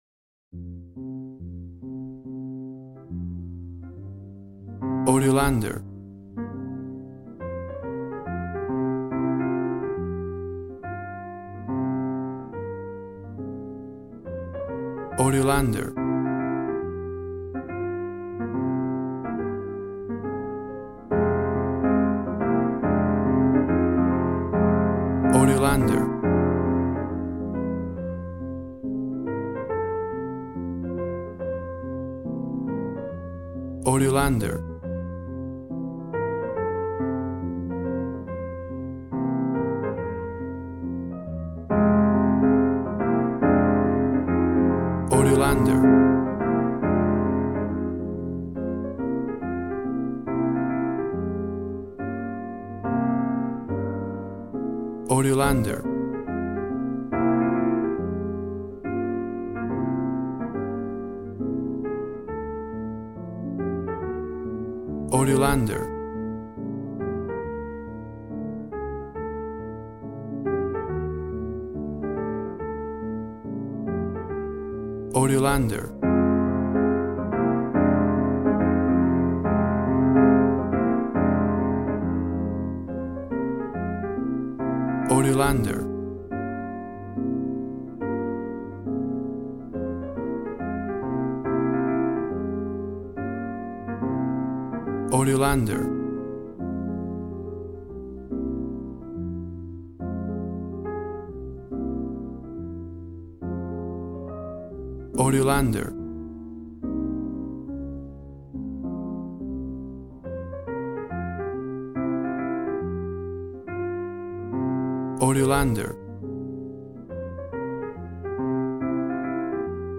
Smooth jazz piano mixed with jazz bass and cool jazz drums.
Tempo (BPM) 140